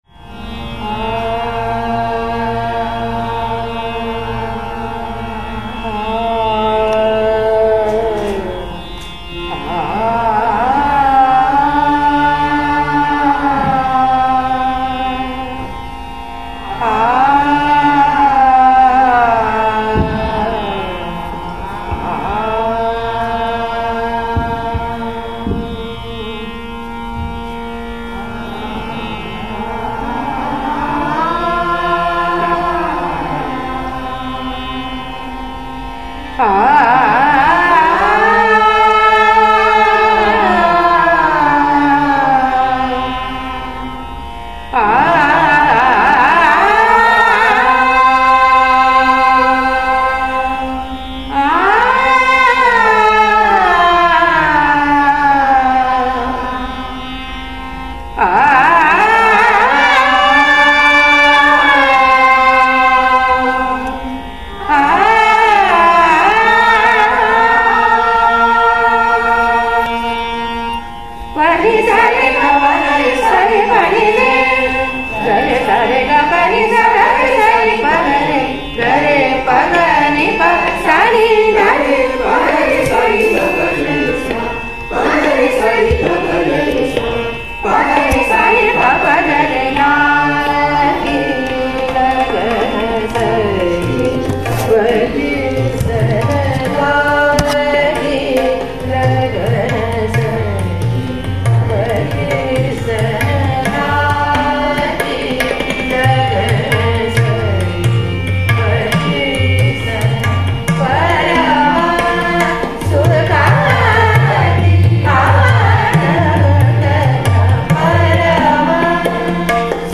Singing classes
They presented the three songs they had learned before a campus audience on April 16th, 2009